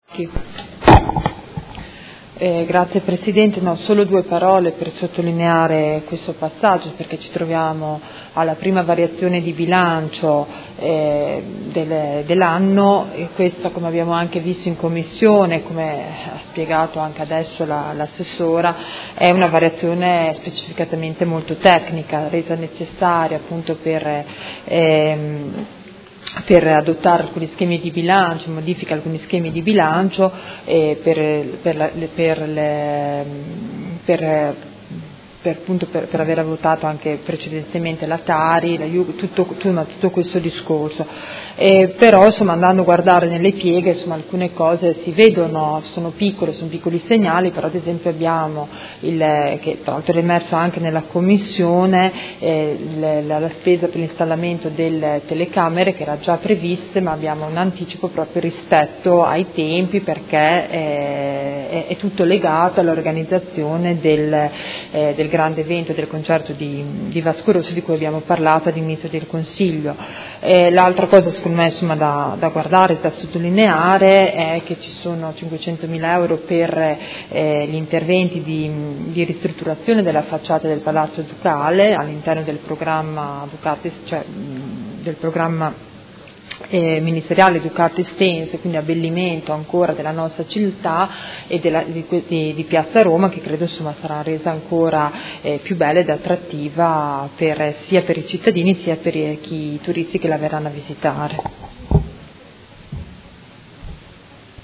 Grazia Baracchi — Sito Audio Consiglio Comunale